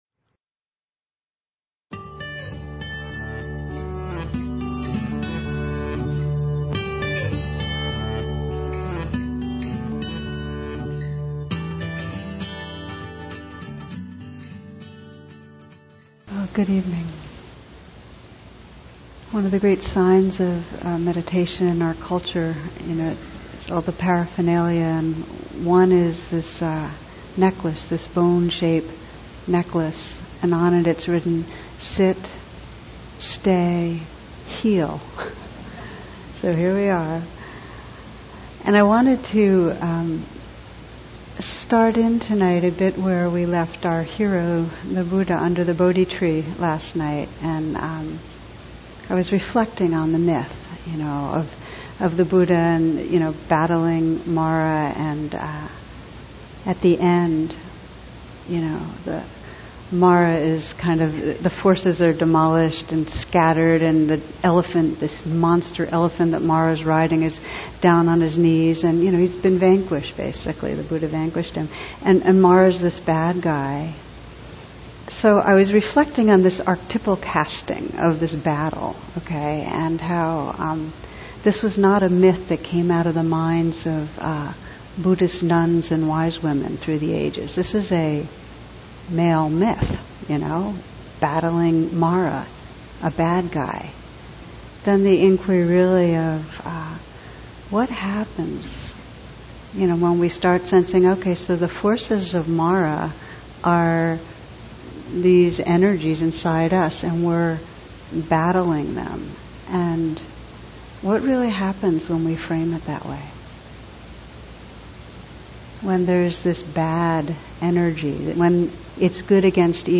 Tara Brach : Remembering Love (Retreat Talk).